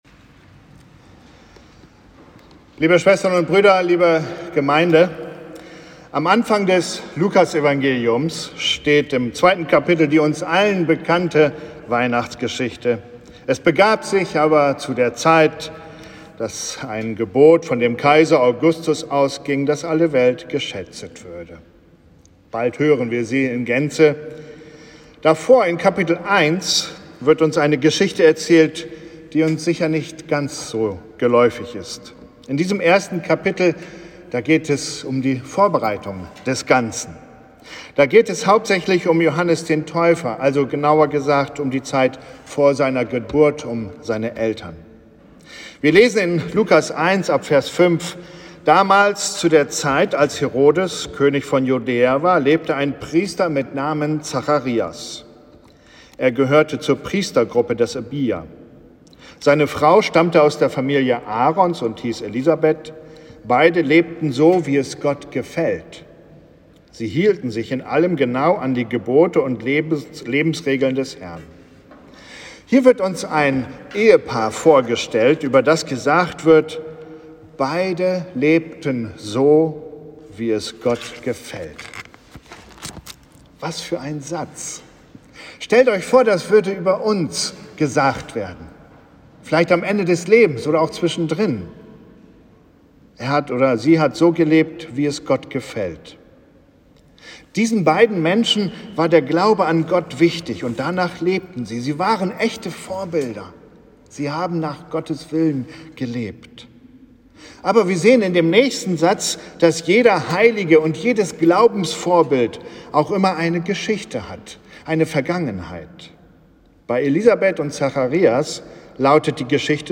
Gottesdienst am 3. Advent
Predigt-zum-3.-Advent-Elisabeth-und-Zacharias-Lukas-1.mp3